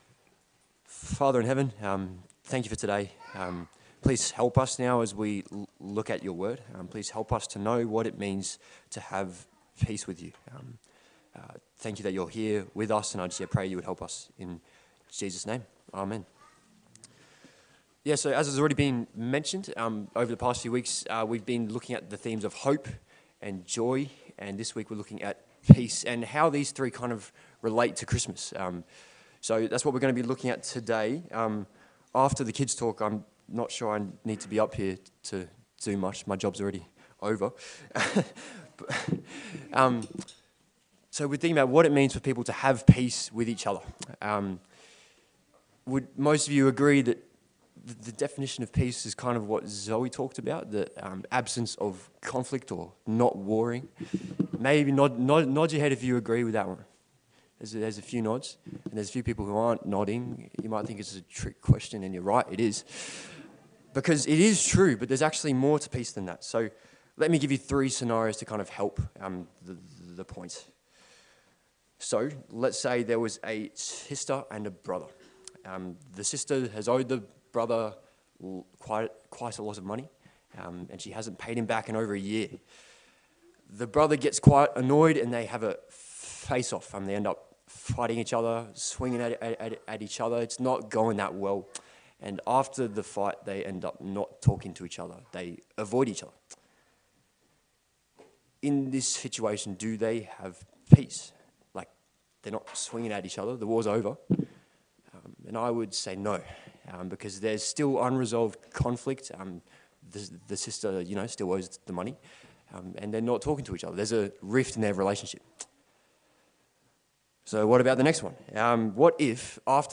Service Type: Sunday Service A sermon leading up to Christmas from the book of Romans